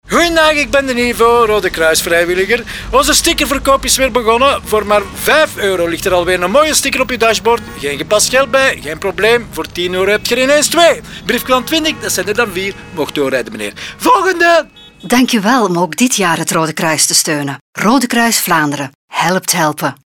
Zend jij deze radiospot (in bijlage) vanaf maandag 16 april (vanaf 12u) mee uit?